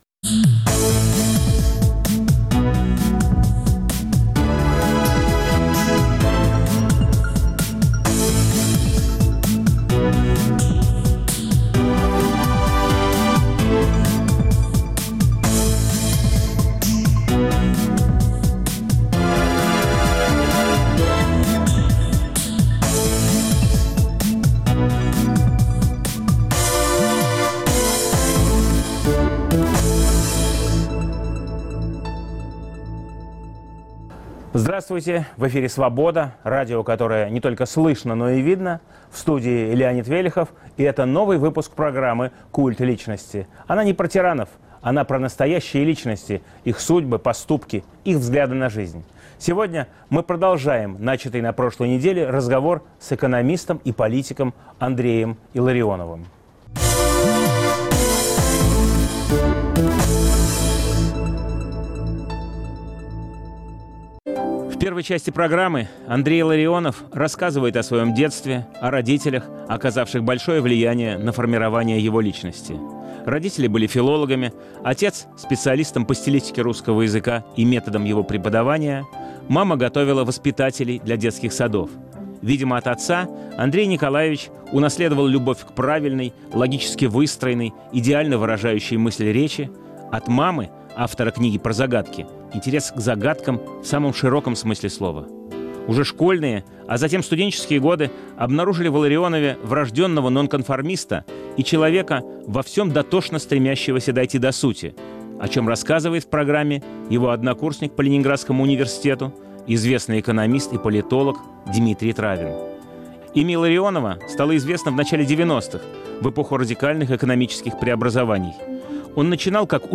Новый выпуск программы о настоящих личностях, их судьбах, поступках и взглядах на жизнь. В студии экономист и политик Андрей Илларионов.